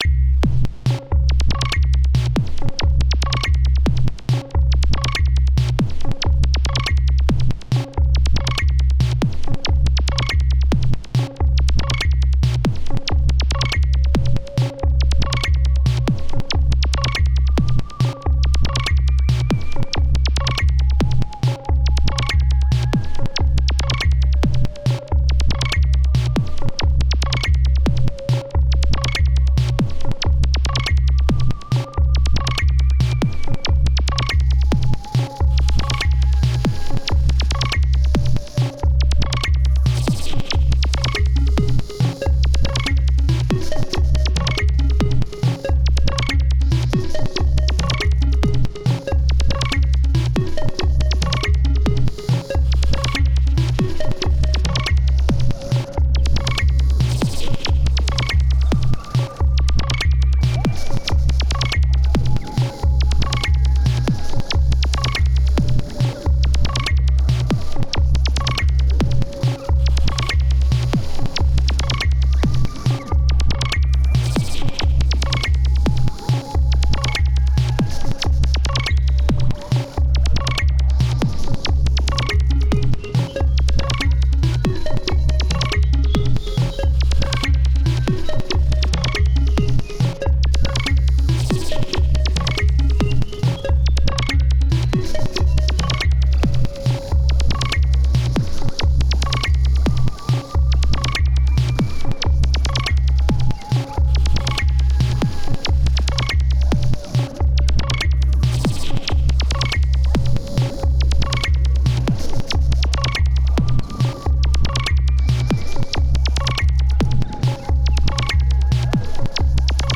Genre: IDM, Eexperimental.